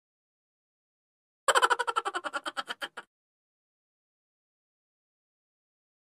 funny-laugh-sound-effect-for-your-vlog_CSm3QTB.mp3